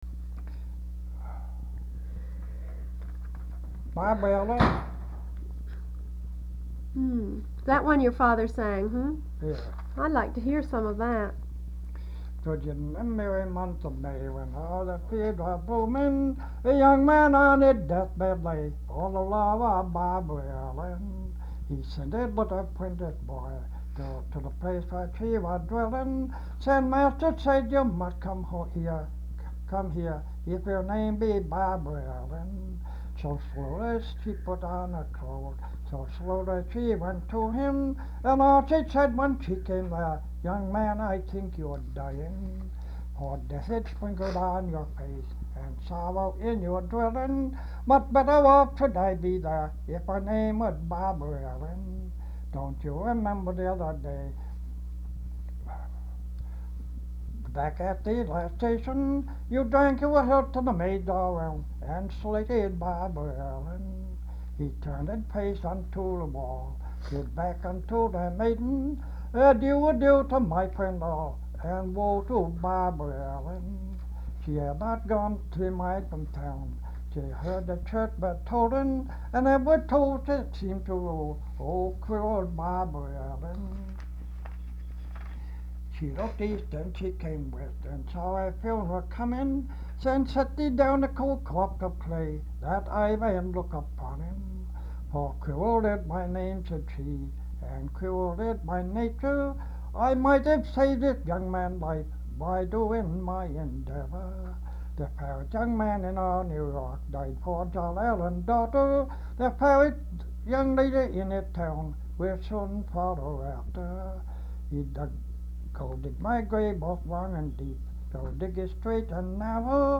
Folk songs, English--Vermont (LCSH)
sound tape reel (analog)